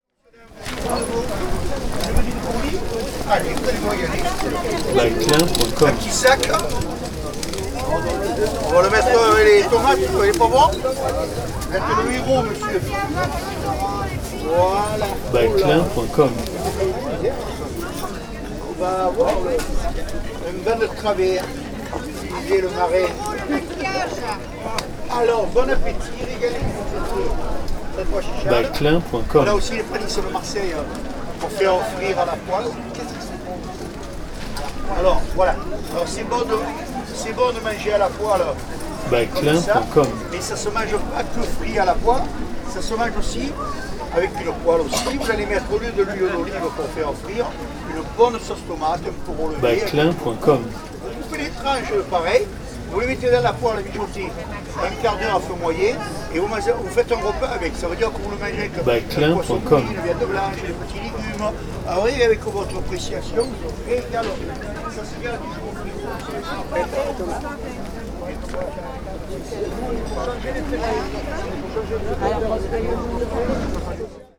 Ambiance du marché : La recette | Photo vidéo du Luberon libre de droit
En passant par le marché d'Apt en Luberon...